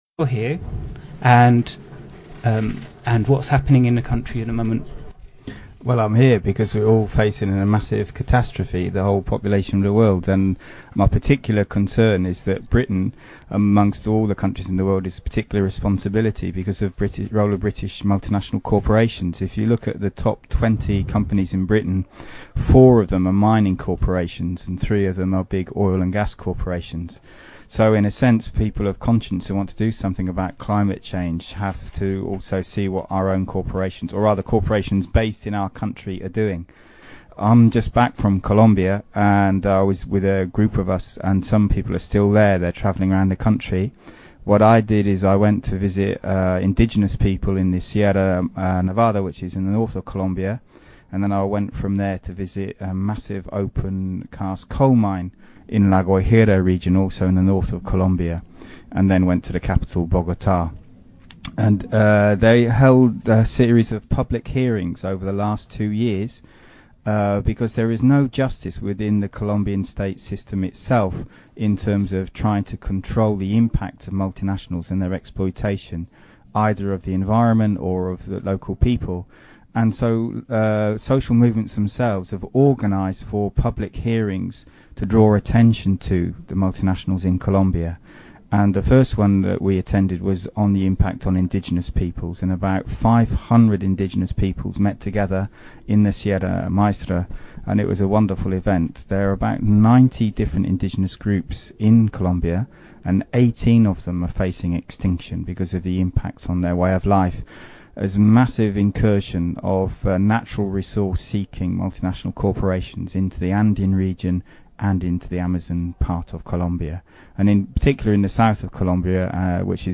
Audio is clipped at the beginning, the interviewer asks – Why are you here at the Climate Camp?